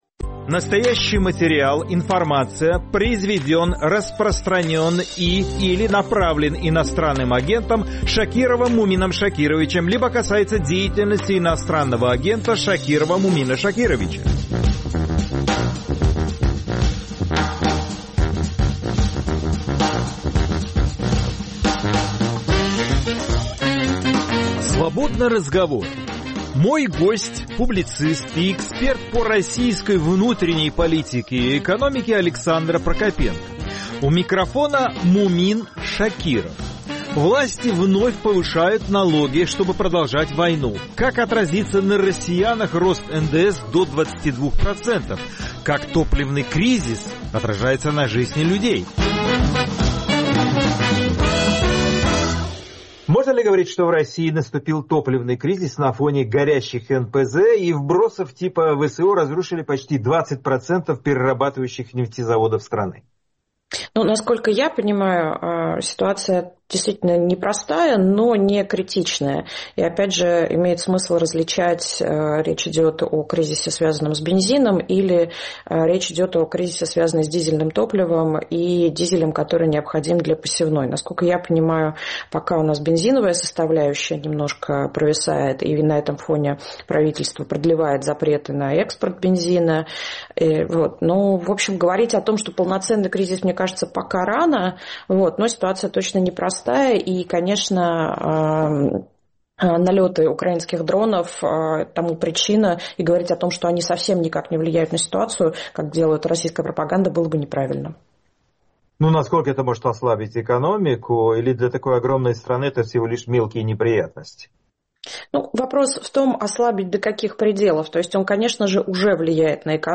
Свободный разговор c экономистом